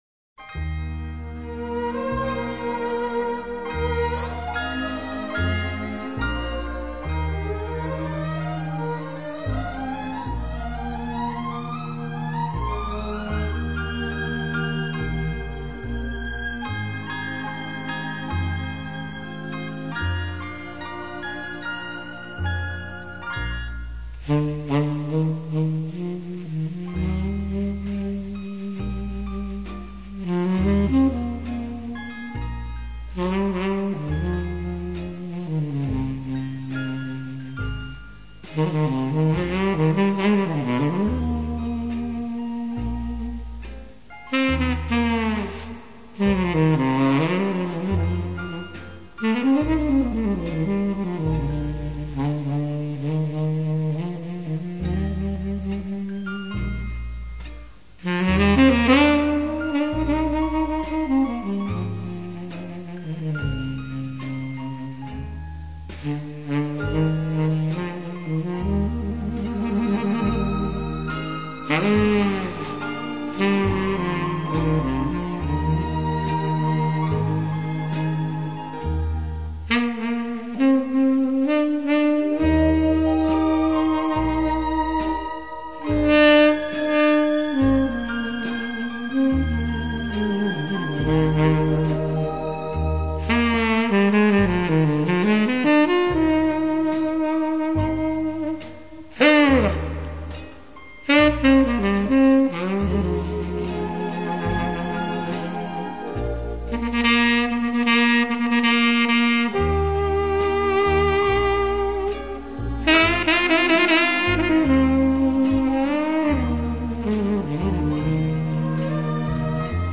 Жанр: Jazz, Instrumental, Easy Listening